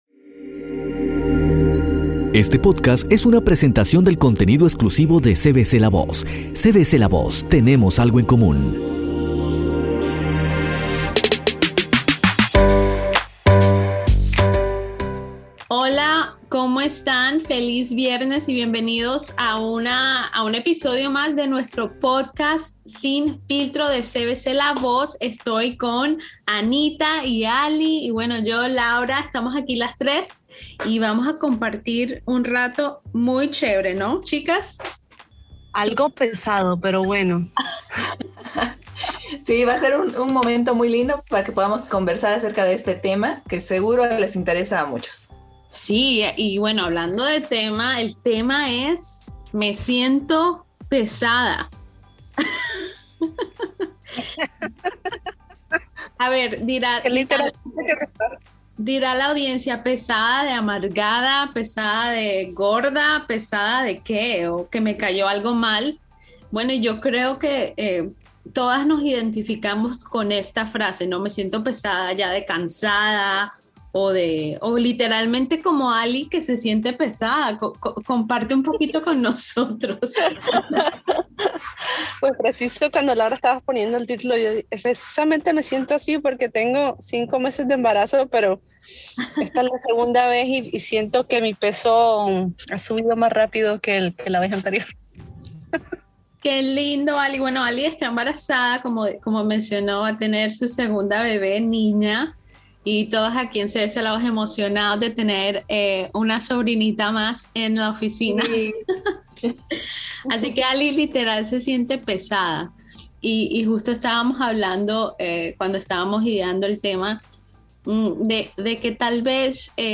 Algo con lo que muchas personas luchan durante toda su vida puede ser el sobre peso. En este episodio de Sin filtro, las chicas de la oficina